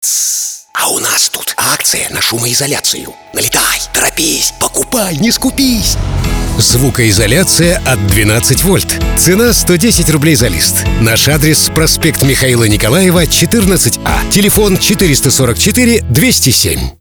Примеры аудиороликов